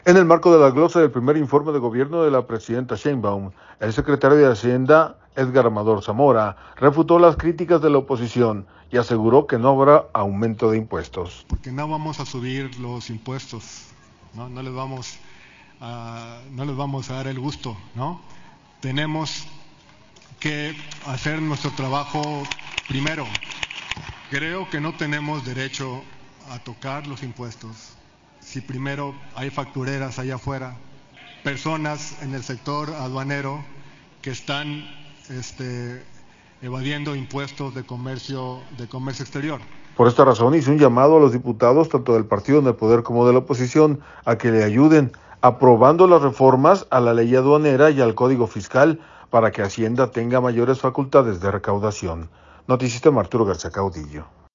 En el marco de la Glosa del primer informe de gobierno de la presidenta Sheinbaum, el secretario de Hacienda, Edgar Amador Zamora, refutó las críticas de la oposición y aseguró que no habrá aumento de impuestos.